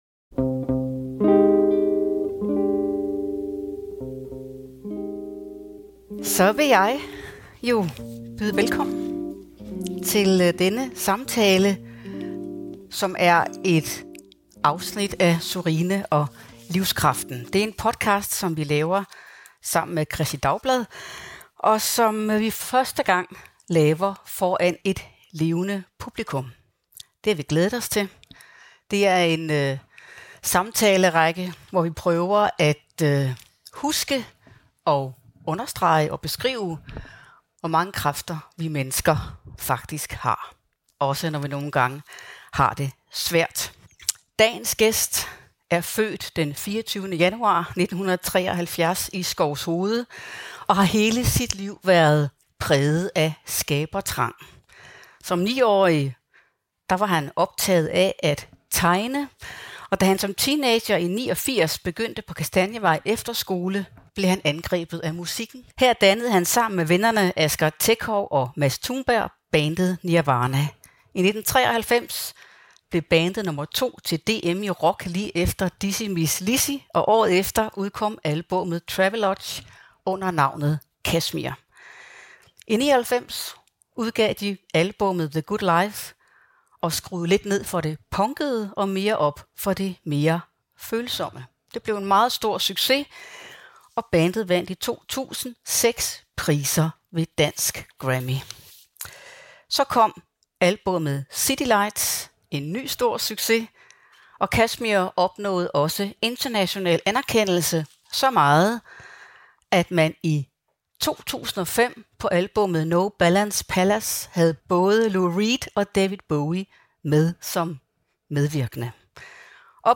I denne podcastserie taler hun i hvert afsnit med en gæst, der deler en personlig fortælling om at finde livskraften i et definerende øjeblik.